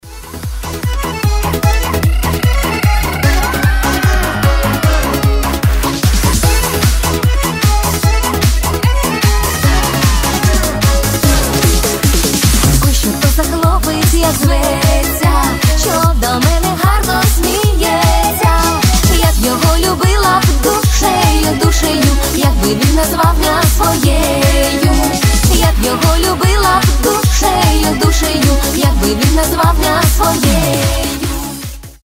• Качество: 320, Stereo
Народные
эстрадные